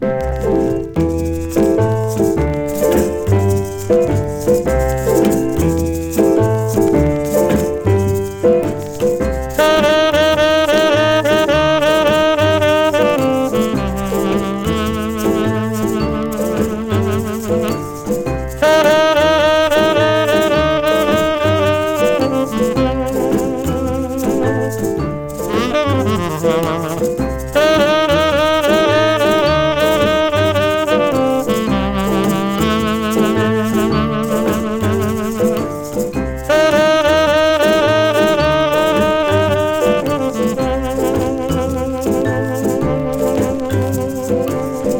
Jazz, R&B　France　12inchレコード　33rpm　Mono